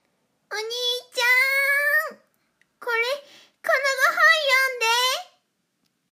サンプルボイス ロリっ子